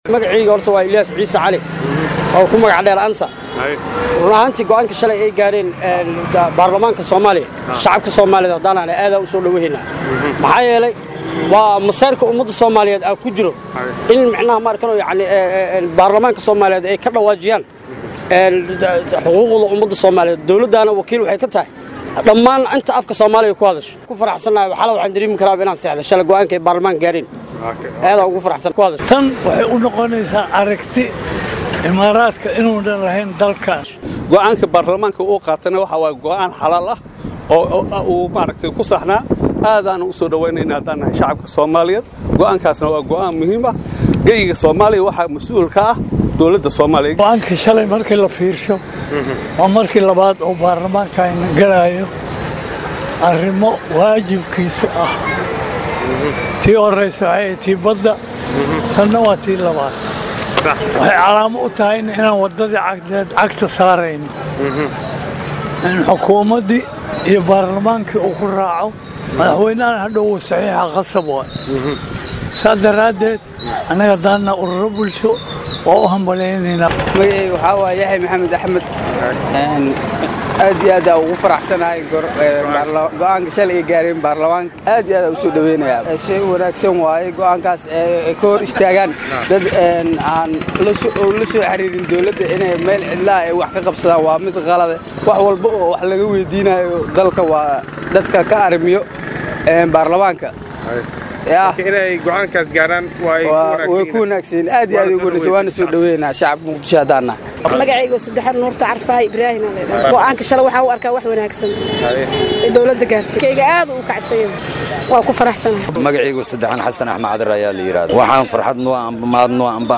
Qaar ka mid ah qeybaha bulshada oo la hadlay Warbaahinta Qaranka Soomaaliya ka sheegay in ay soo dhaweynayaan go,aanka Golaha shacabka Federaalka Soomaaliya iyo guudahaanba Dowladu ka qaadatay heshiiska wax kama jiraanka ahaa oo Maamulka Soomaliland la galay shirka dhismaha dekedaha ee Imaaraadka DP WORLD.
Codadka bulshada